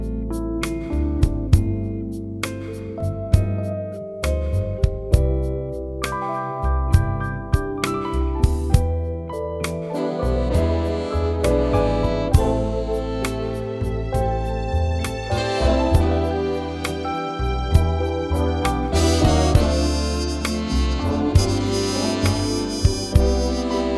Two Semitones Down Jazz / Swing 4:24 Buy £1.50